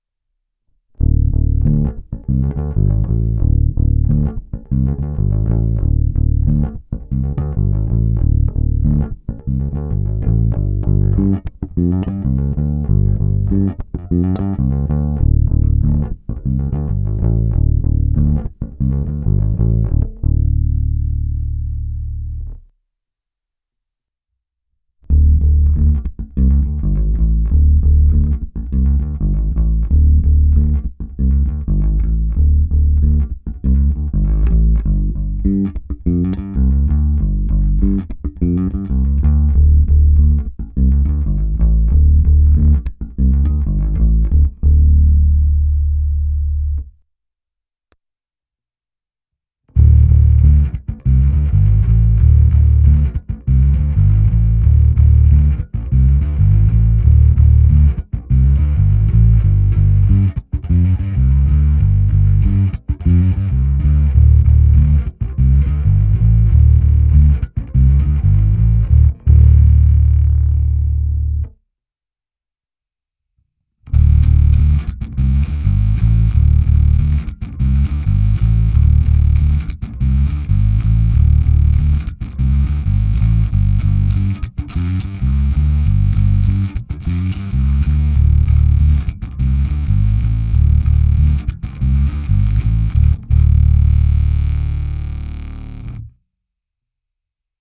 Nahrál jsem je s mou baskytarou Fender American Prefessional II Precision Bass V s niklovými roundwound strunami, které pravda už nejsou sice nejčerstvější, ale stále jsou slušné. První část je zvuk samotné baskytary rovnou do zvukové karty, následuje ukázka čistého zvuku přes ADAMa se simulací aparátu a kompresorem, pak ukázka zkreslení, které jsem pro sebe našel jako víceméně použitelné, a kdy jsem se opět snažil ho alespoň trochu přiblížit k mému zvuku přes zmiňovaný Microtubes X Ultra, a nakonec jsem udělal pokus o fuzz.